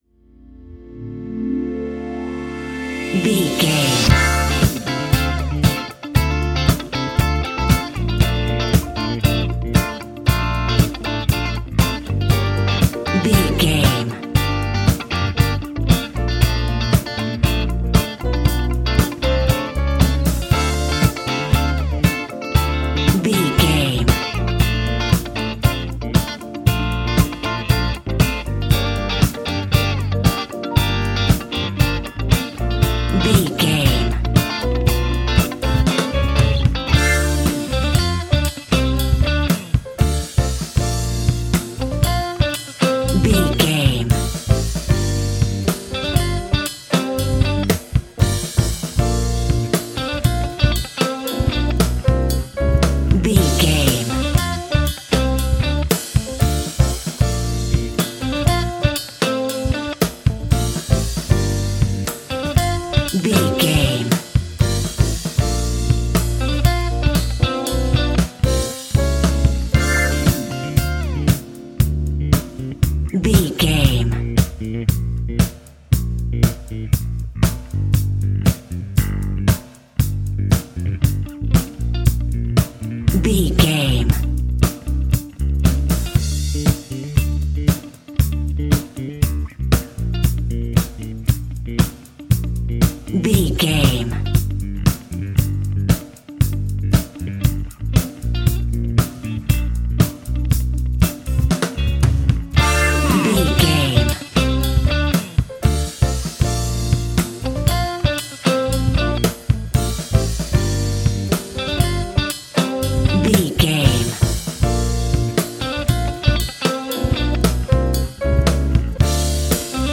Ionian/Major
A♯
house
electro dance
synths
techno
trance
instrumentals